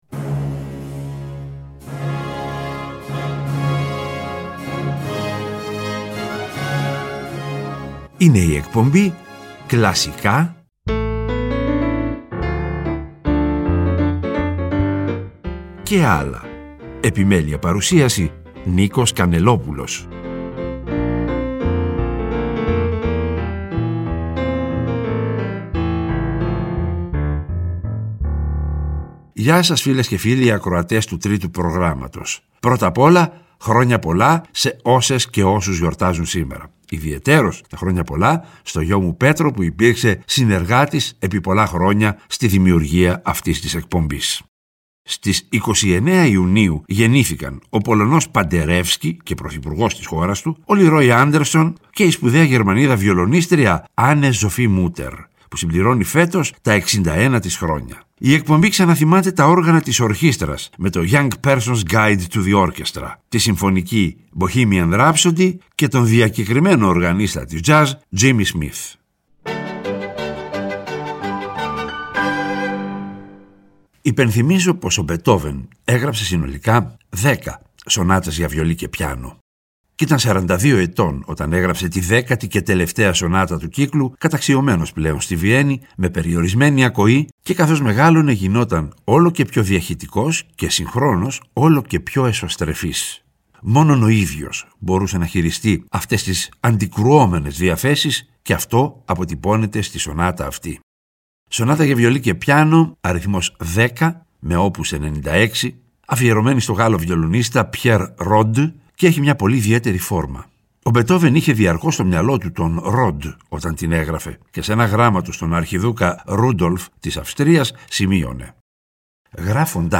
Και, προς το τέλος κάθε εκπομπής, θα ακούγονται τα… «άλλα» μουσικά είδη, όπως μιούζικαλ, μουσική του κινηματογράφου -κατά προτίμηση σε συμφωνική μορφή- διασκ